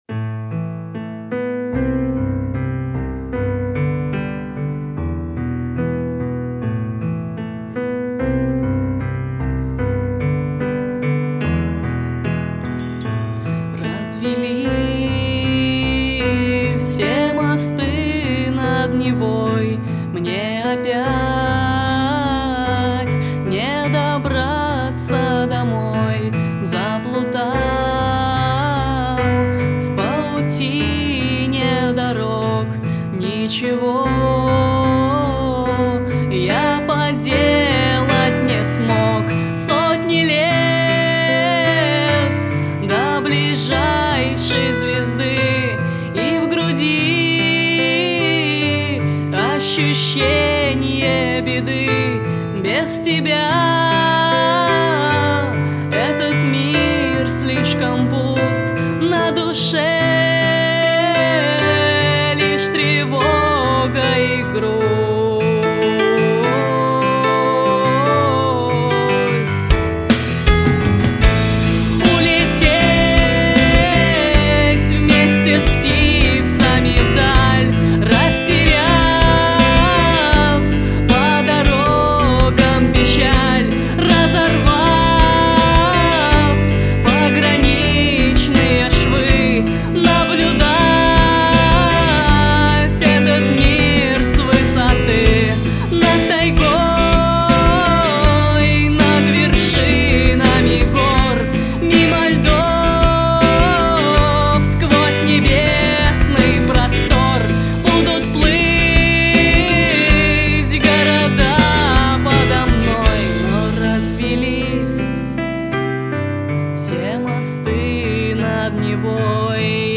Аранжирована и записана летом 2008 года
на студиях "Хата" и "MappleTree Studio" (обе - Минск).